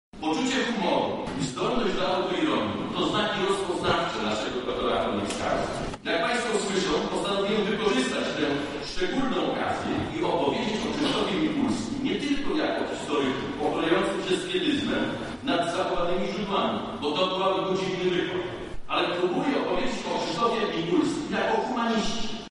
Wydarzenie odbyło się w Auli Wydziału Humanistycznego UMCS.